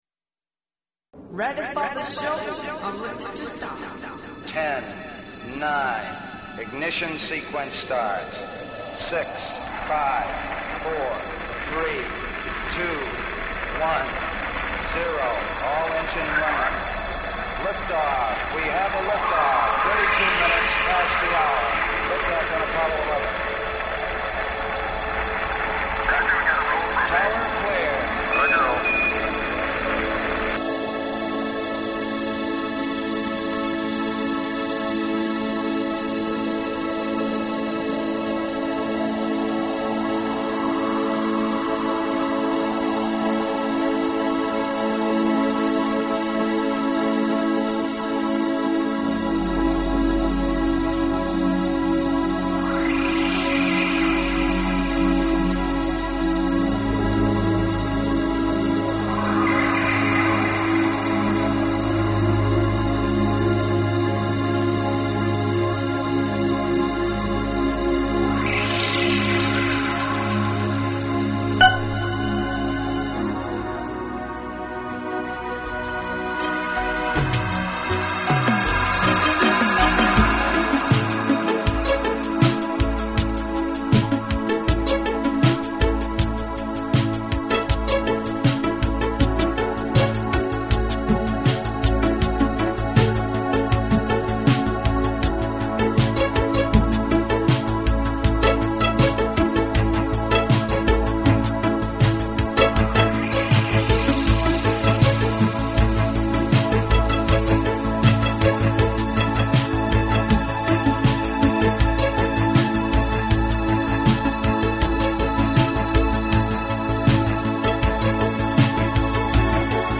Talk Show Episode, Audio Podcast, ET-First_Contact_Radio and Courtesy of BBS Radio on , show guests , about , categorized as